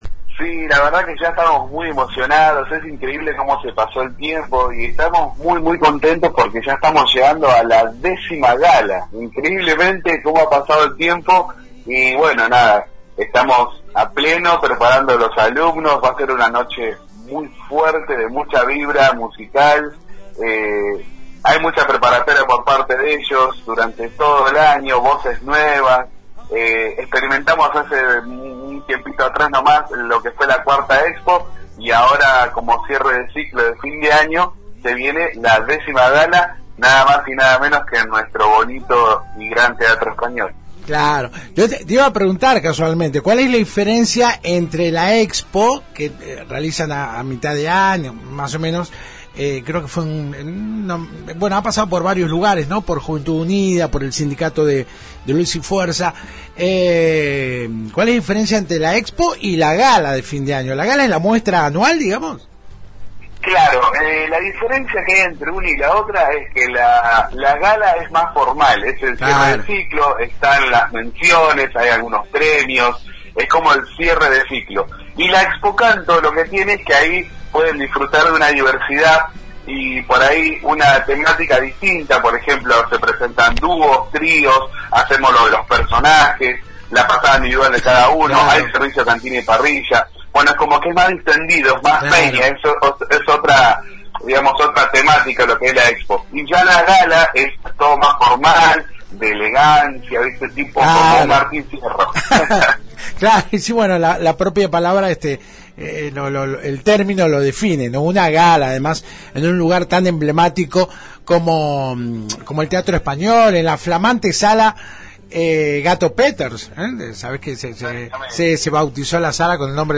El reconocido cantante contó este jueves en FM Alpha los preparativos para el esperado encuentro.